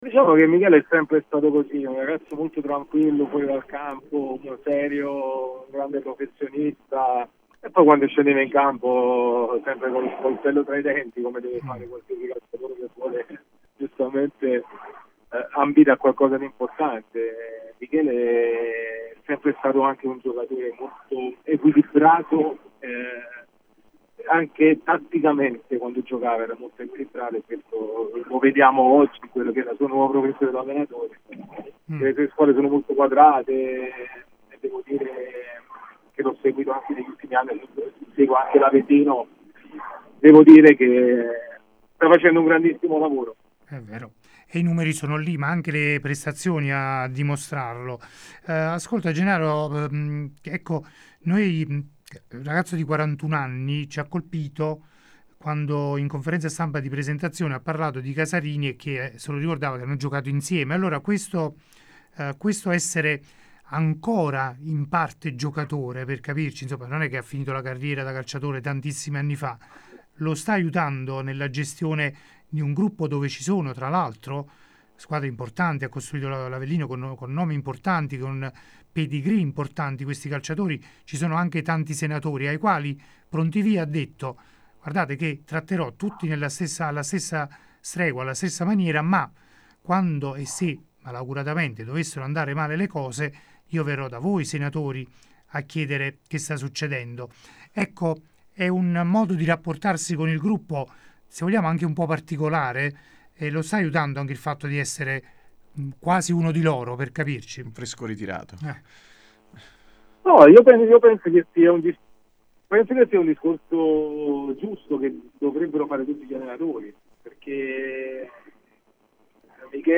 Ospite de Il Pomeriggio da Supereroi di Radio Punto NuovoGennaro Iezzo, storica bandiera del Napoli nonché ex compagno di mister Pazienza.